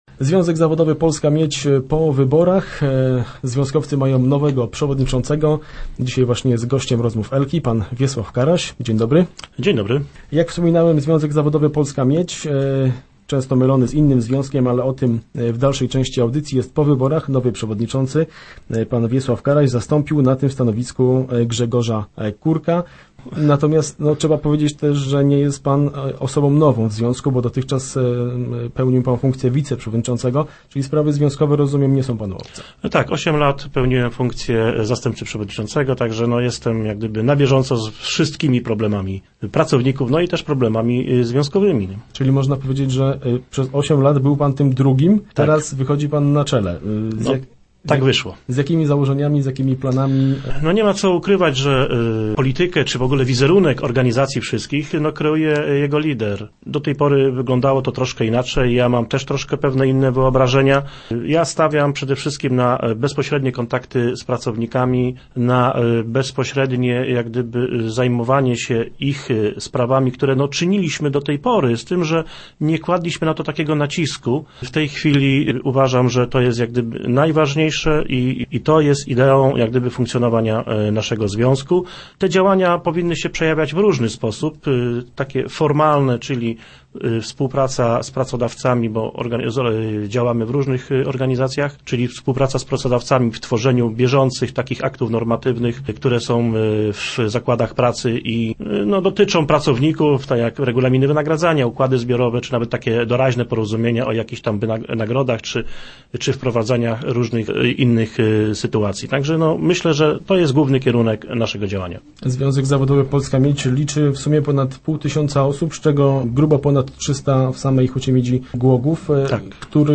był gościem piątkowych Rozmów Elki.